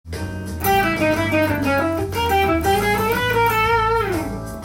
④のフレーズは、Aブルーノートスケールで始まり
A#ディミニッシュスケールクロマチックスケールを使用しています。
３つ共に不安定で緊張感があるスケールなので
安定感はありませんが、玄人好みのフレーズです。